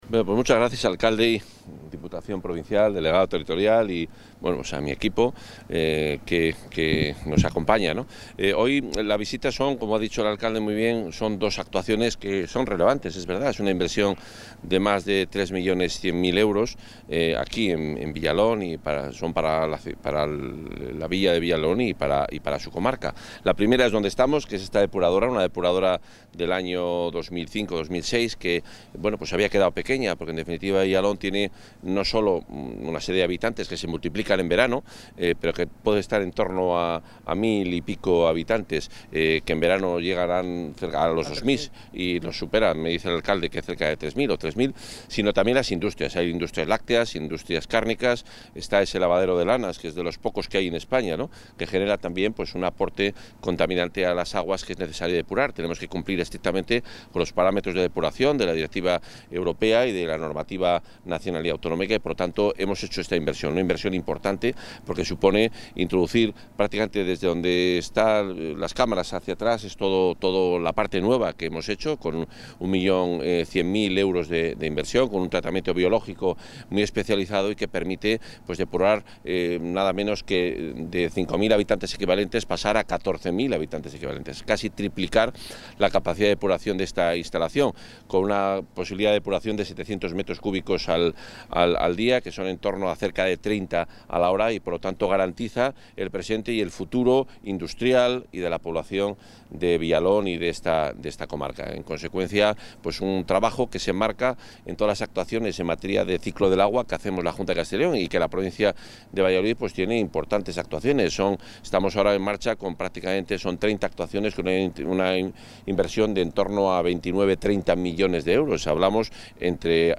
Intervención del consejero en funciones.
El consejero de Fomento y Medio Ambiente en funciones, Juan Carlos Suárez-Quiñones, ha visitado la estación depuradora de aguas residuales (EDAR) de la localidad vallisoletana de Villalón de Campos, pendiente solamente de su puesta a punto tras las obras de ampliación, que han ascendido a 1,1 millones de euros, financiadas por la Junta a través de Somacyl.